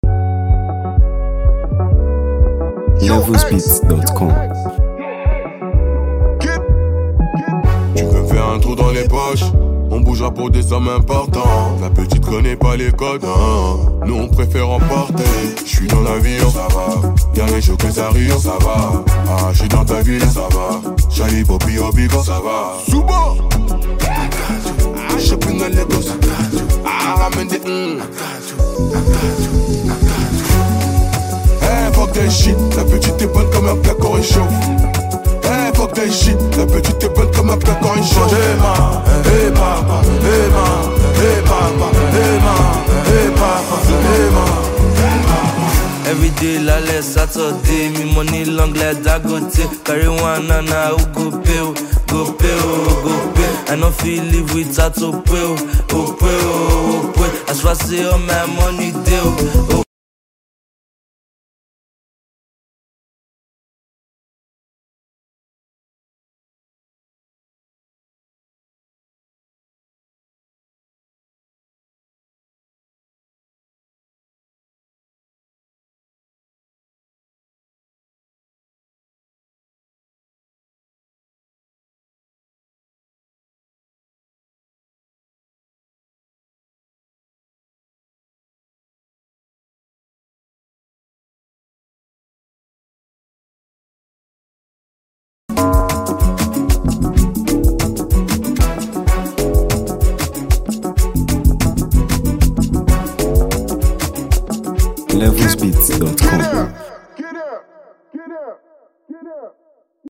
delivering infectious vibes, catchy melodies